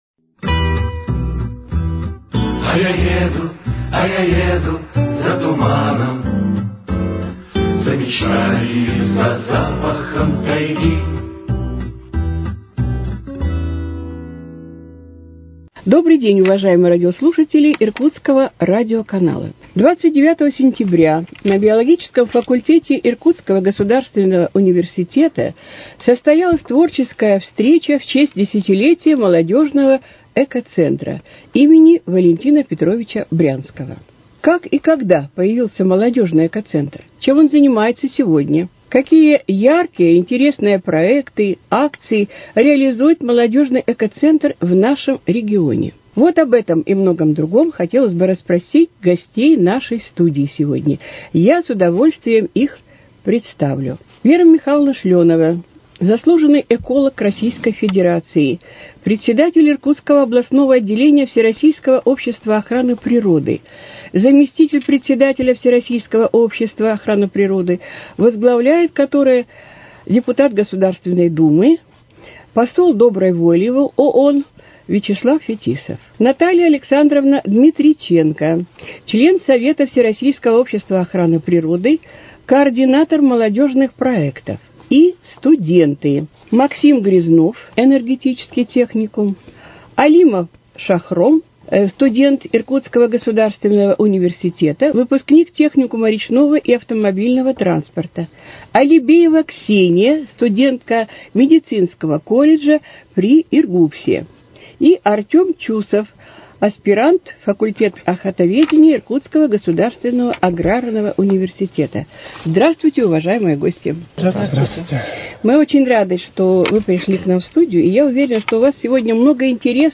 Гостями экологической гостиной на сей раз стали студенты из разных вузов, которые являются членами Молодежного экологического центра имени Валентина Петровича Брянского.